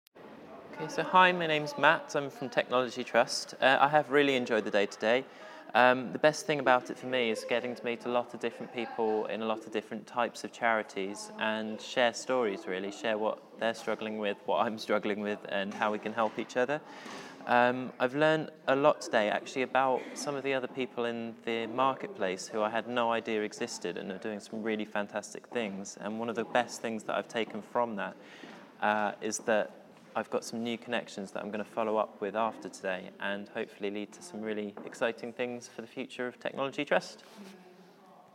Connecting the crowd at Impact Aloud 2016